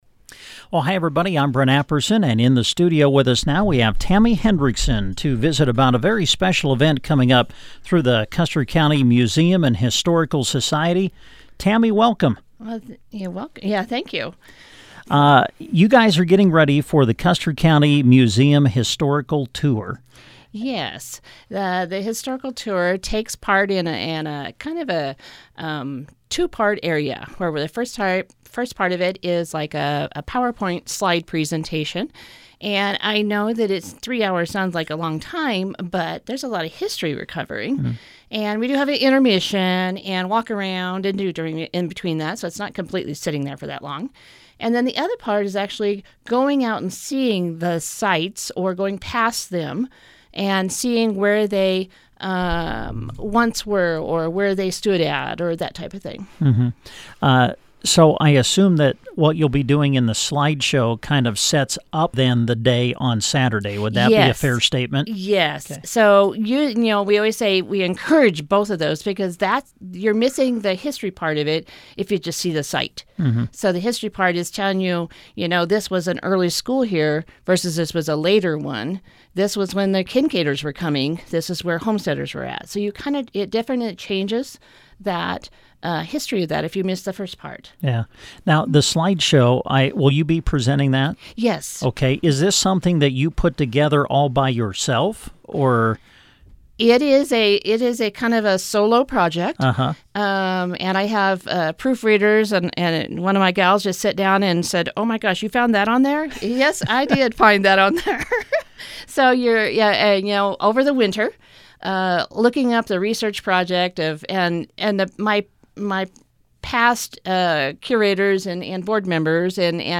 MUSEUM-INTERVIEW_.mp3